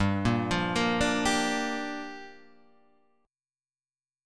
G Minor 7th